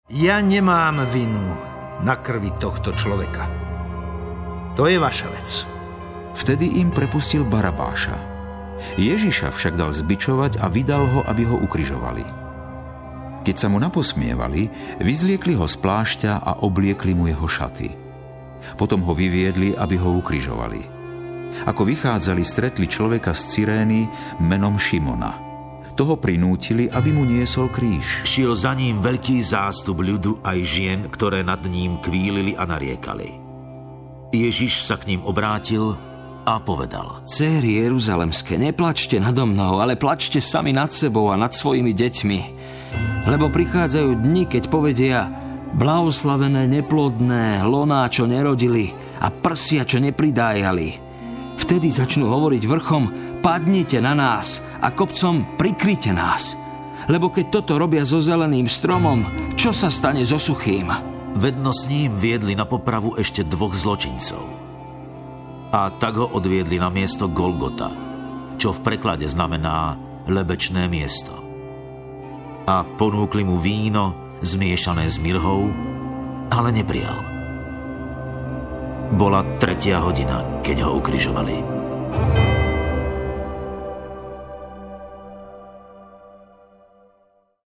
Rodinná edícia biblických príbehov formou rozhlasovej dramatizácie.
Príbehy k vám domov prinesú známi slovenskí herci.